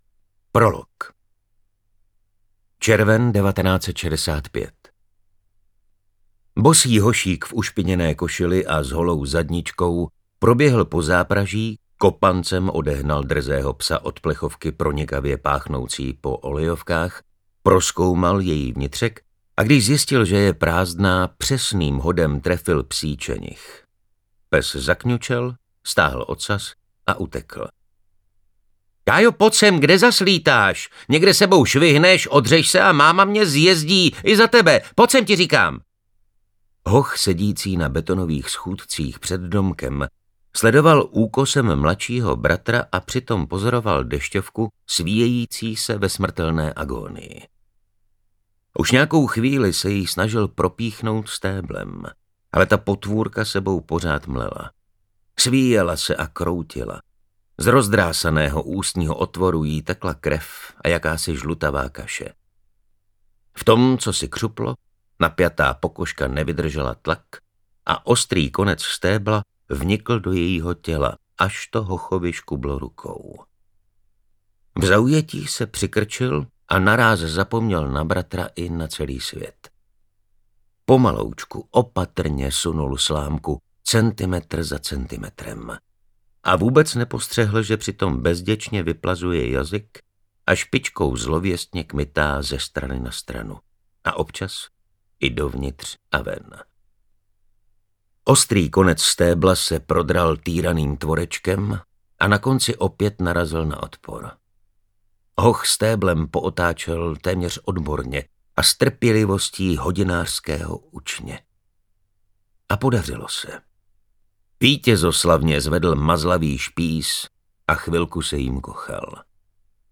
Bestie audiokniha
Ukázka z knihy
• InterpretMartin Stránský